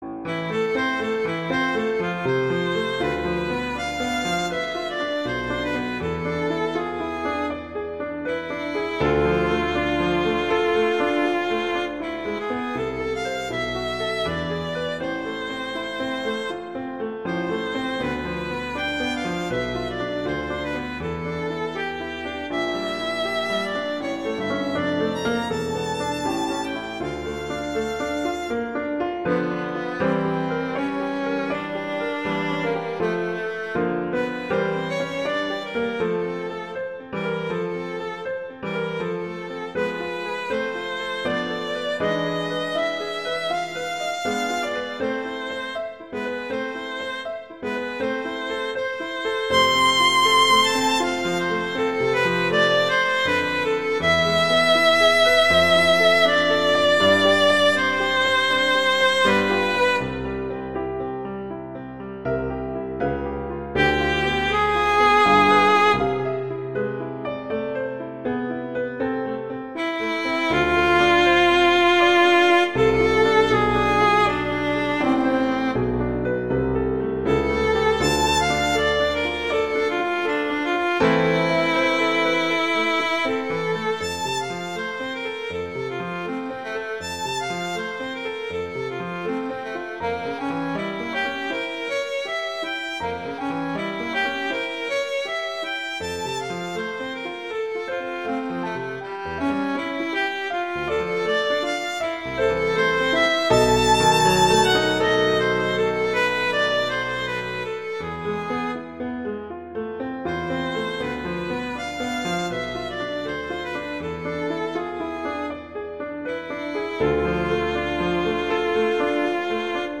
classical
A minor, A major